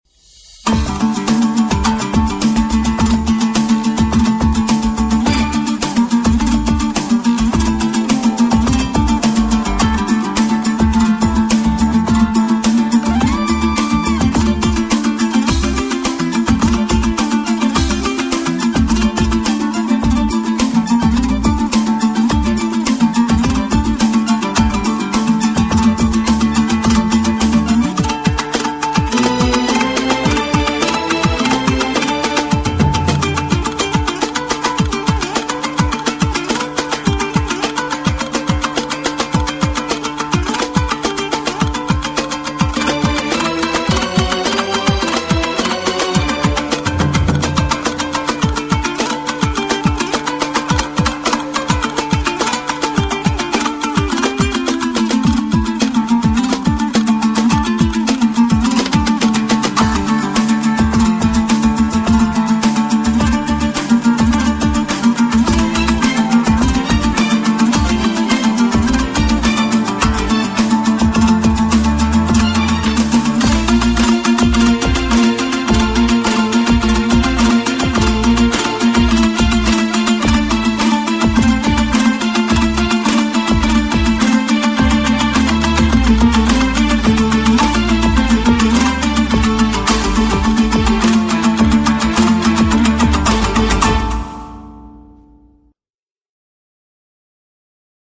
• Качество: 110, Stereo
восточные мотивы
без слов
инструментальные
Народные
казахские
домбра